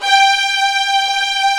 Index of /90_sSampleCDs/Roland LCDP13 String Sections/STR_Violins V/STR_Vls8 fff slo